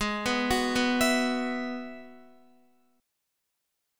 Listen to Abm7#5 strummed